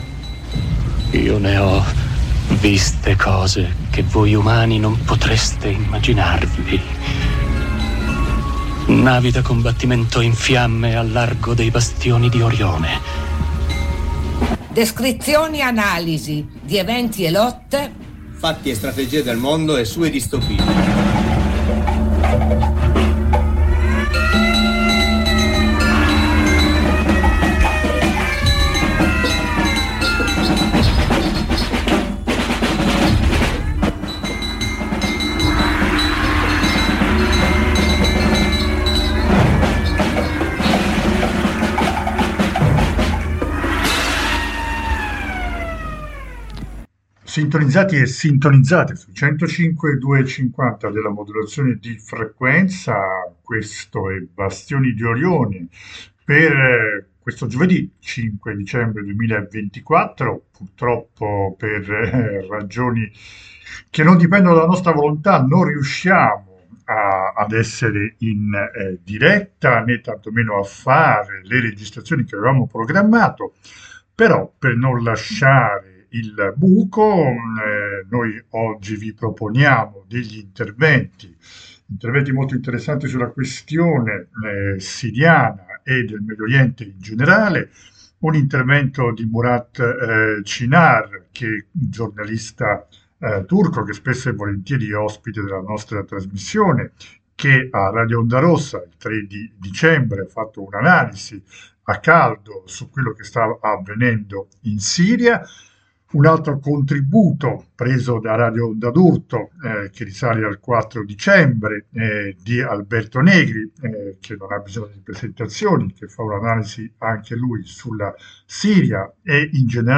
Bastioni in questa puntata propone tre inserti registrati sugli eventi siriani